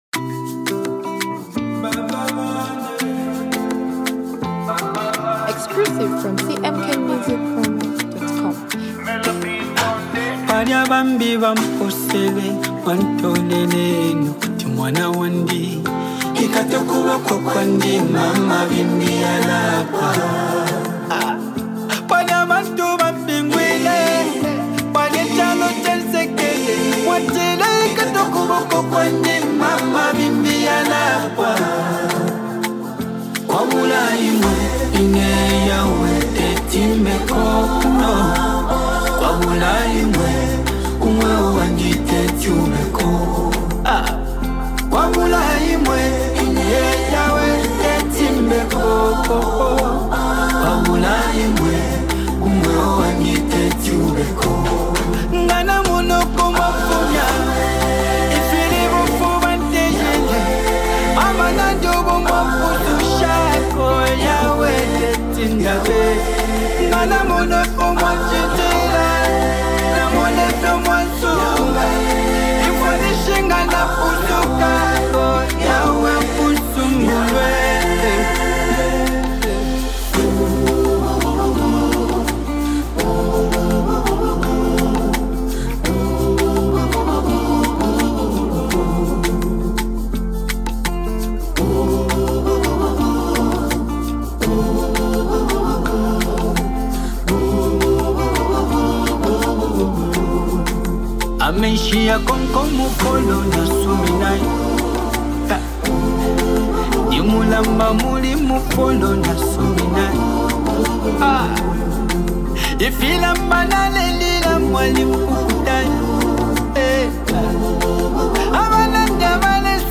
With his powerful vocals and thoughtful lyrics